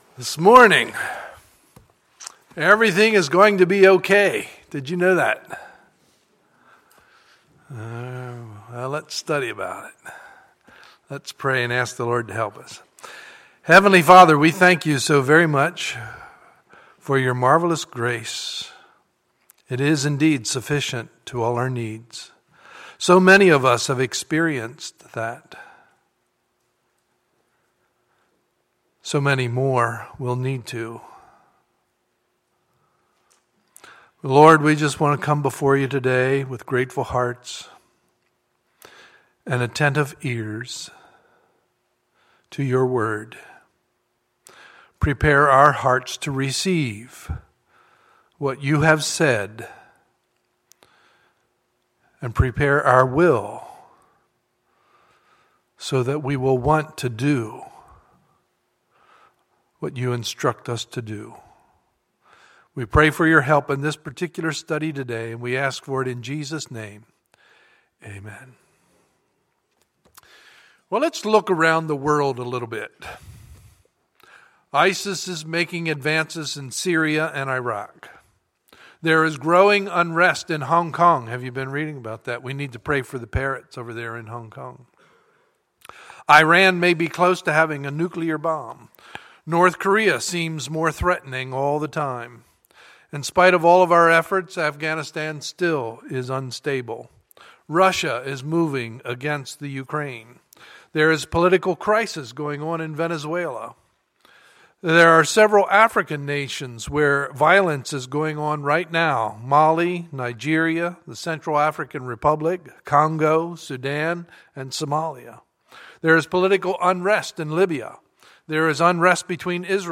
Sunday, October 5, 2014 – Sunday Morning Service